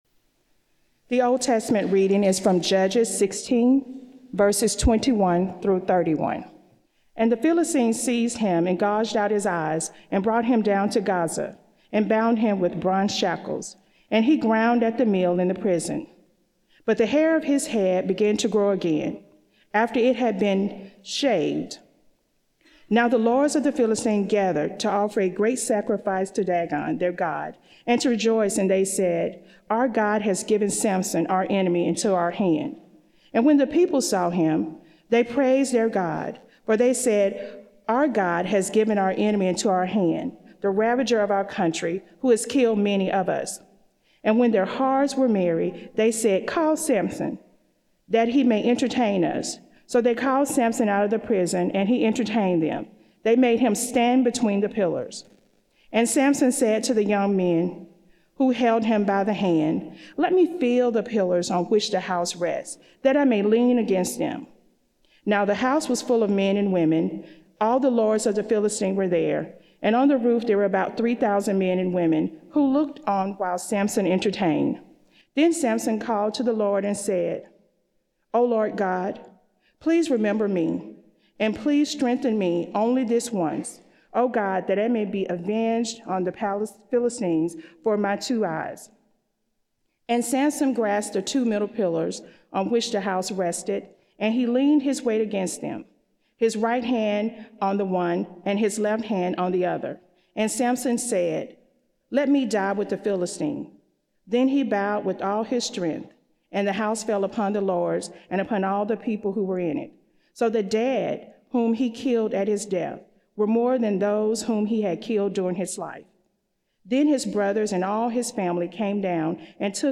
Beeson Divinity School Chapel Services